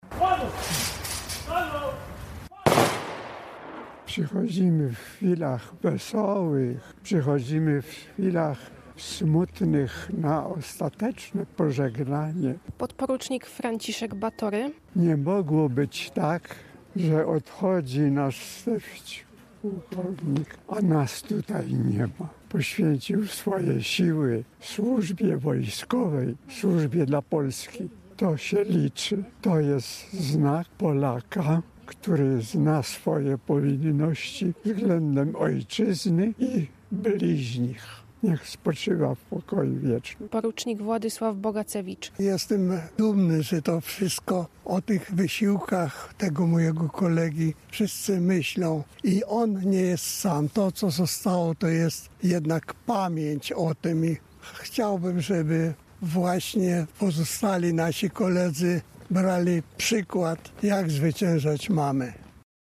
Uroczystości pogrzebowe na Pobitnie z asystą wojskową
Relacja